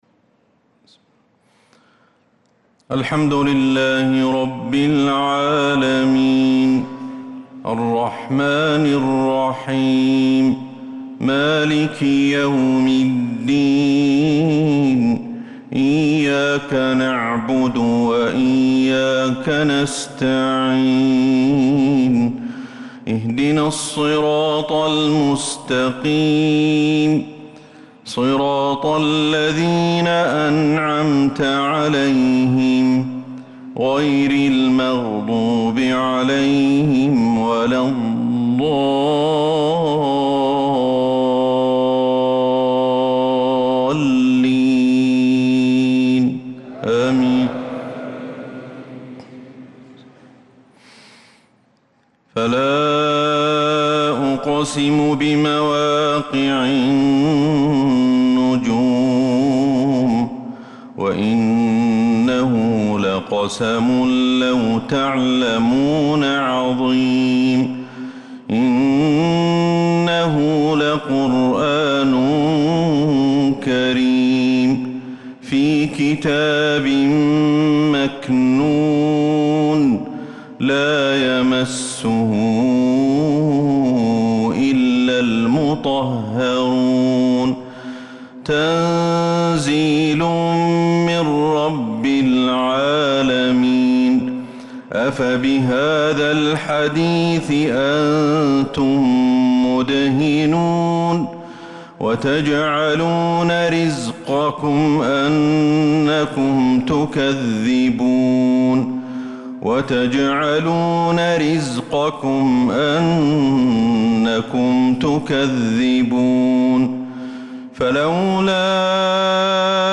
صلاة العشاء للقارئ أحمد الحذيفي 19 ذو القعدة 1445 هـ
تِلَاوَات الْحَرَمَيْن .